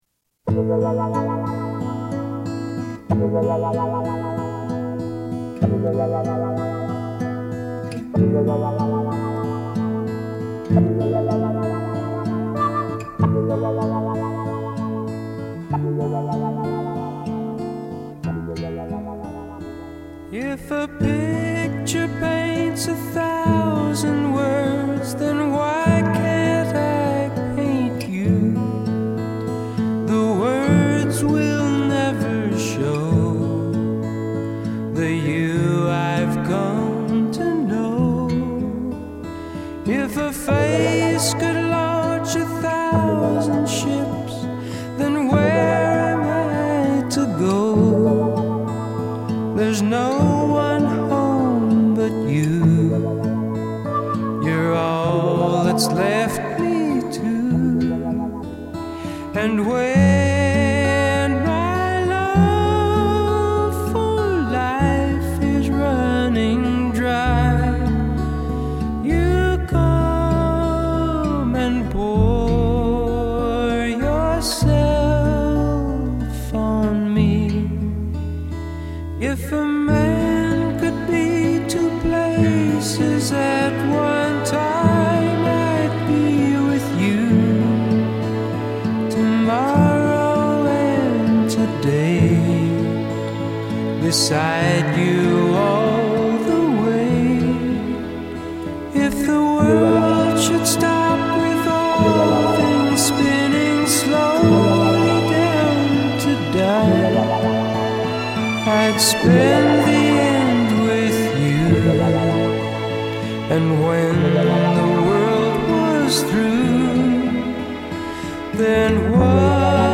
以轻摇滚的曲风唱出柔美的情歌